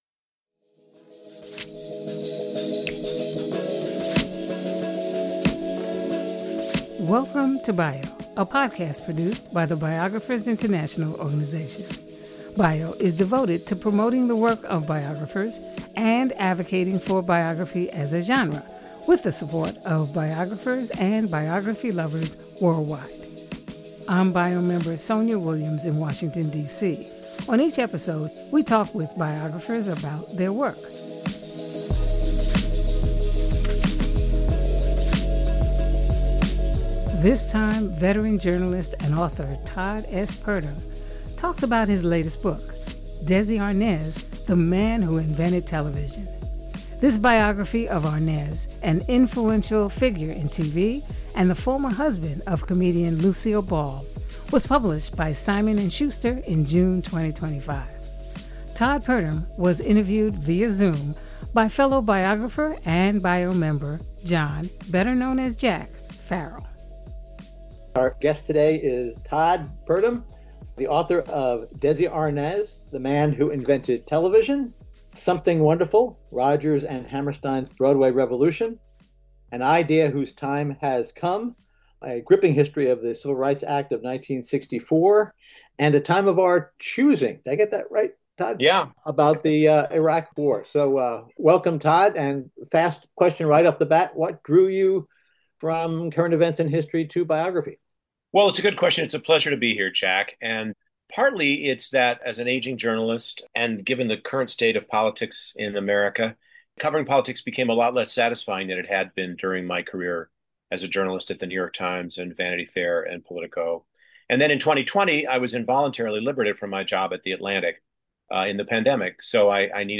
Each week, we post fascinating discussions with biographers from around the country and the world.